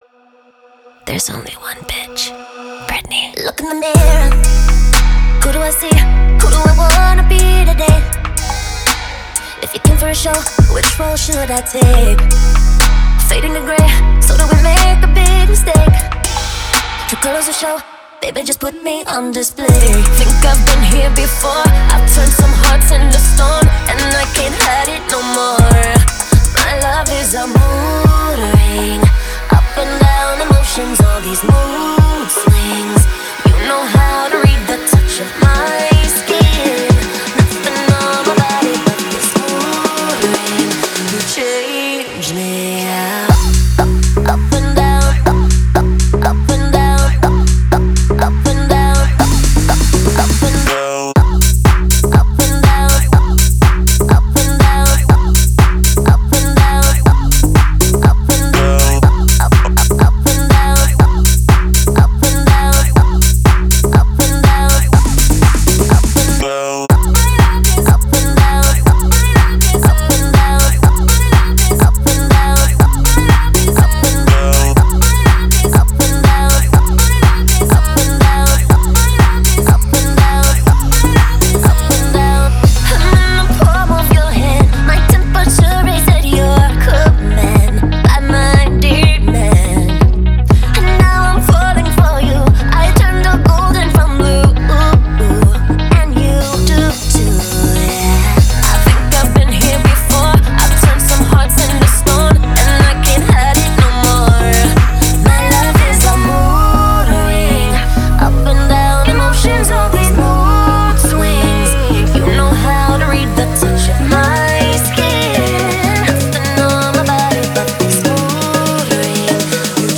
это энергичный трек в жанре электро-pop